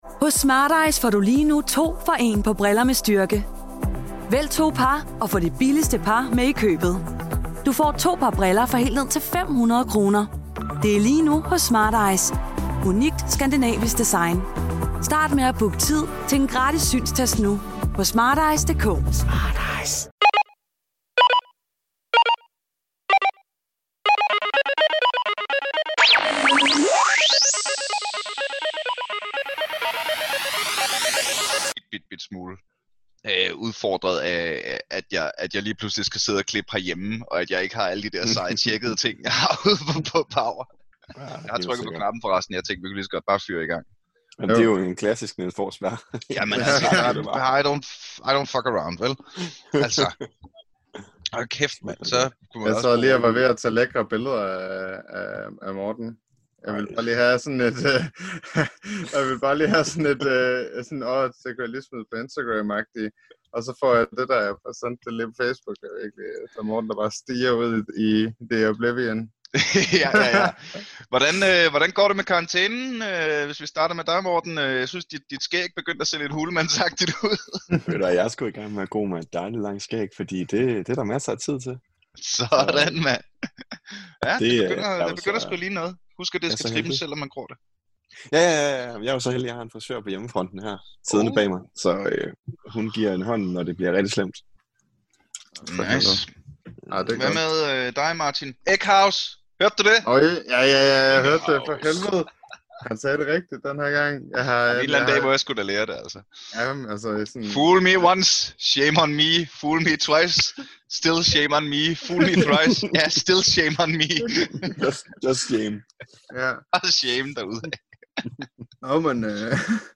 Nu kommer der en ny bolle i Astralis-suppen, og vi sidder 3 gamle mænd og kloger os på verdens største esportsucces.